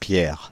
Ääntäminen
Ääntäminen France (Île-de-France): IPA: /pjɛʁ/ Haettu sana löytyi näillä lähdekielillä: ranska Käännöksiä ei löytynyt valitulle kohdekielelle.